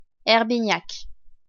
Herbignac (French pronunciation: [ɛʁbiɲak]